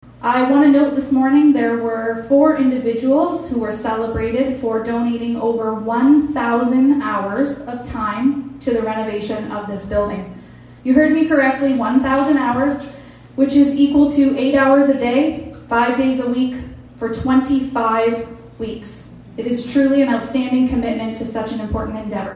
Mayor Amy Martin was also on hand to highlight the importance of the event.